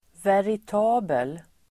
Ladda ner uttalet
Uttal: [verit'a:bel]